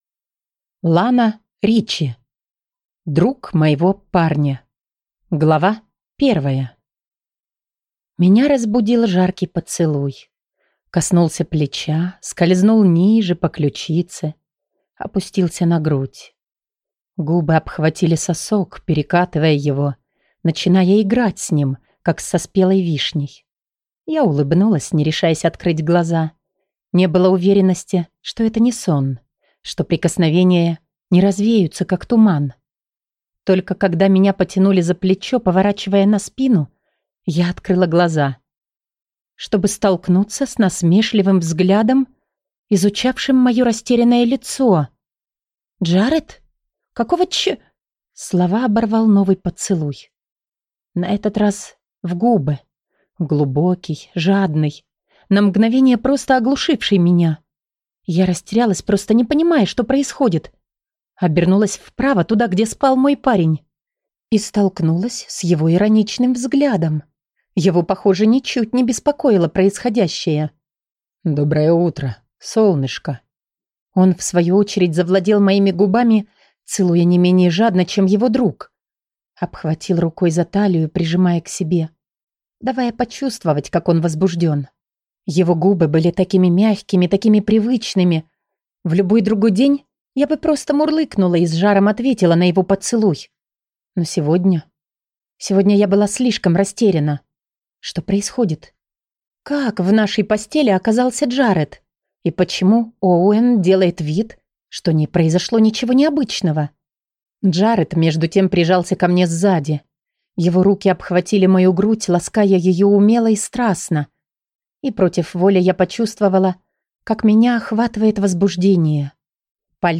Аудиокнига Друг моего парня | Библиотека аудиокниг